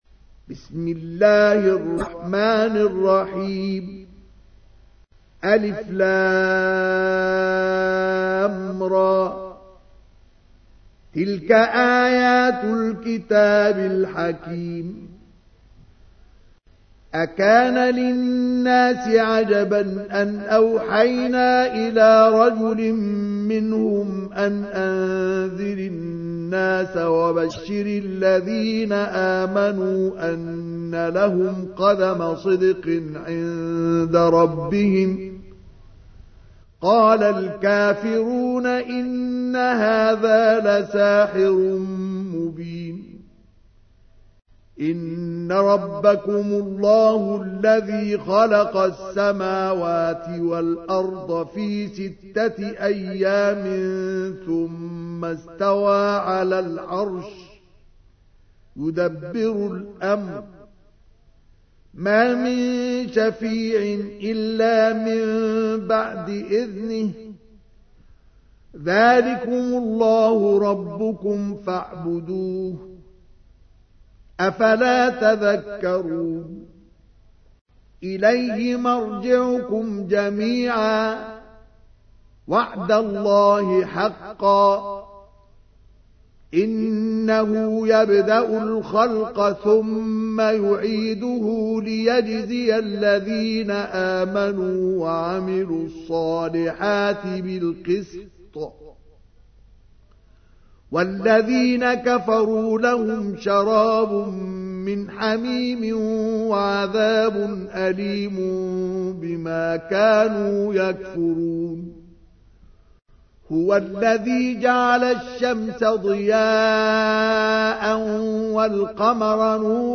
تحميل : 10. سورة يونس / القارئ مصطفى اسماعيل / القرآن الكريم / موقع يا حسين